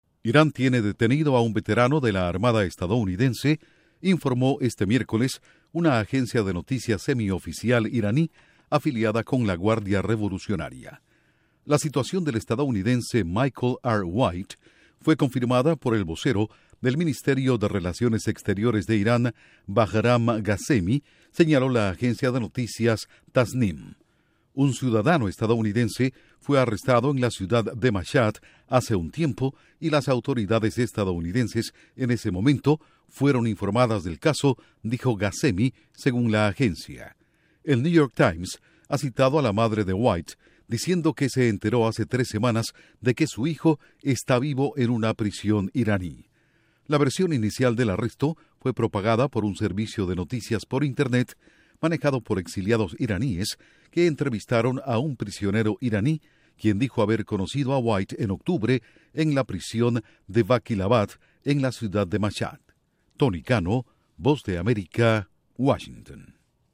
Irán confirma que tiene detenido a veterano de la Armada de Estados Unidos. Informa desde la Voz de América en Washington